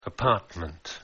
پیش از اینکه وارد مبحث اصلی شویم نگاهی داشته باشید به تلفظ های این دو کلمه:
apartment.mp3